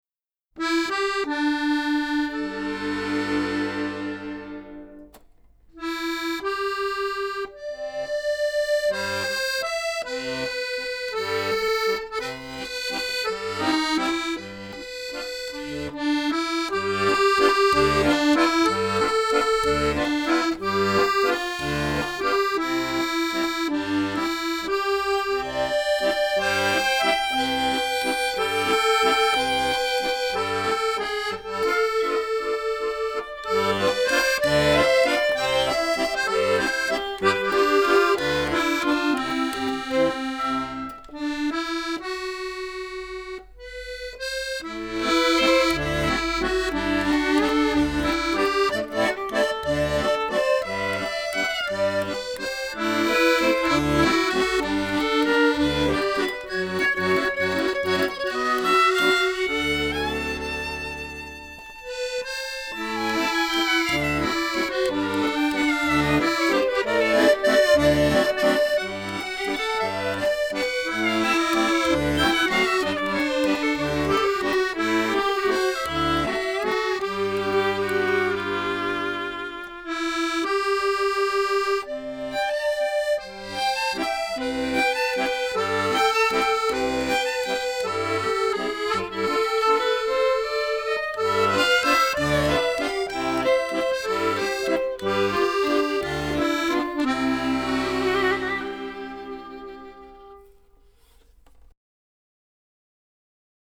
Jazz and Pop with a French Twist - Oh la la !